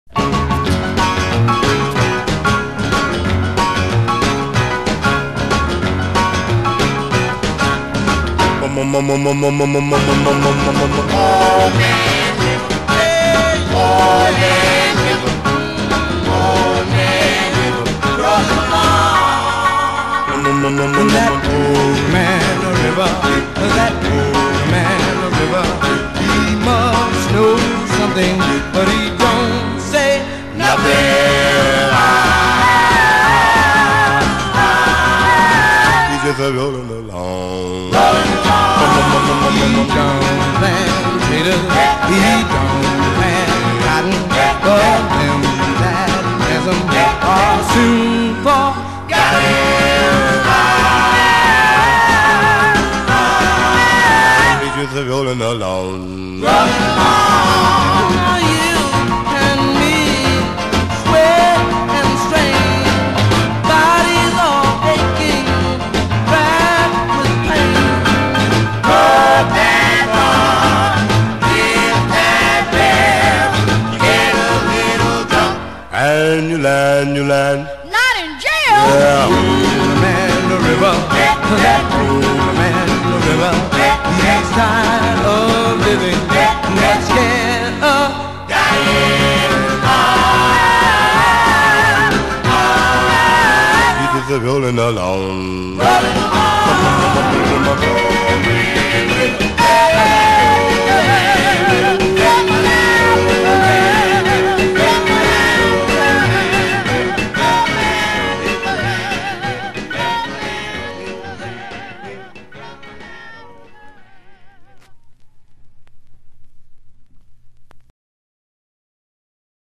Tag: Doo Wop